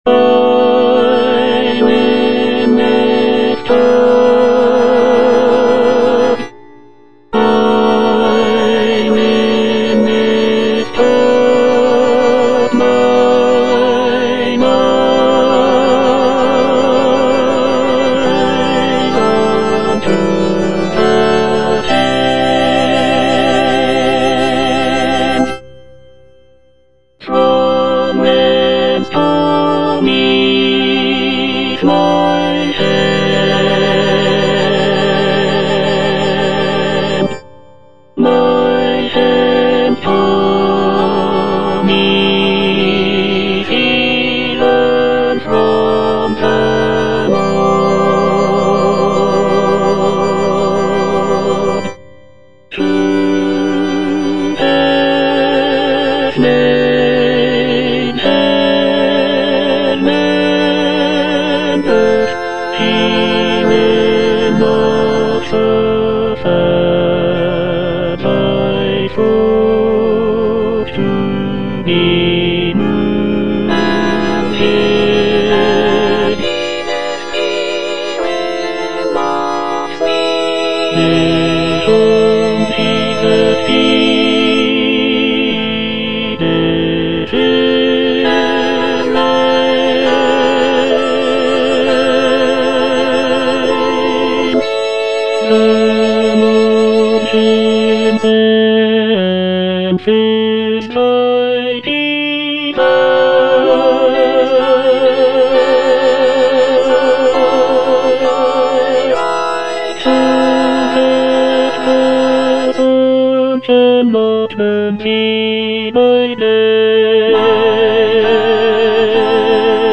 Tenor II (Emphasised voice and other voices)
a choral work